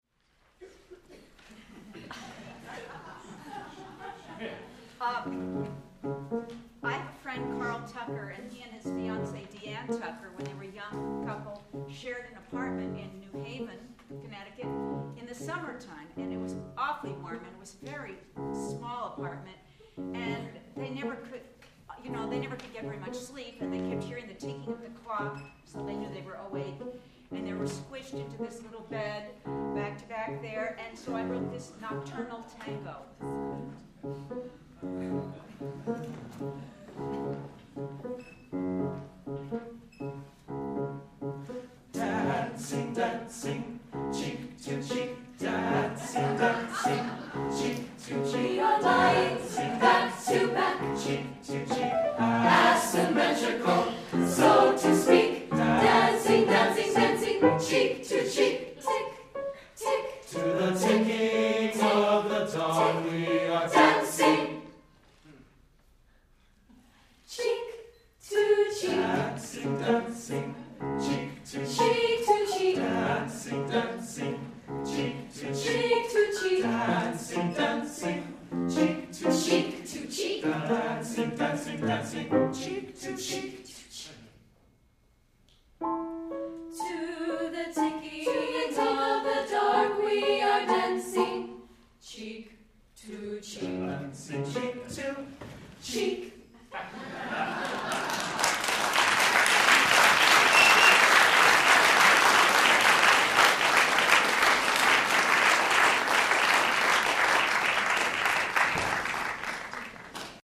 for SATB Chorus and Piano (1978)
"nocturnal tango."